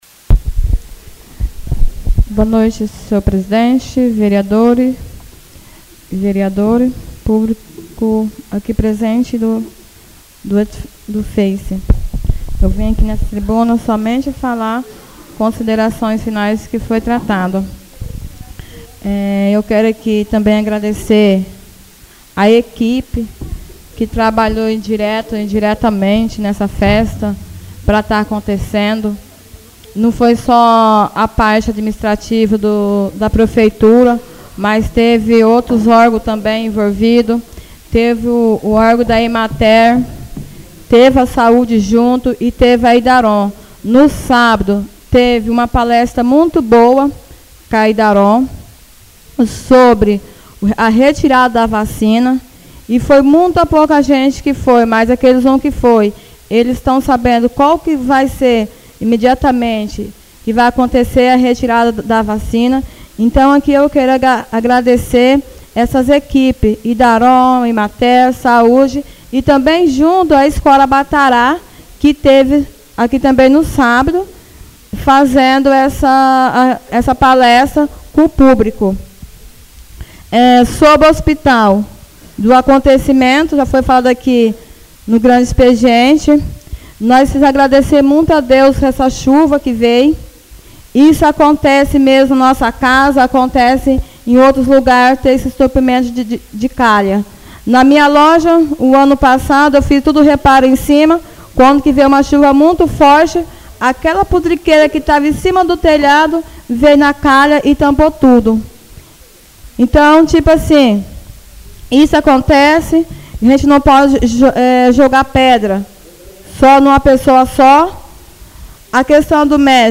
Oradores das Explicações Pessoais (27ª Ordinária da 3ª Sessão Legislativa da 6ª Legislatura)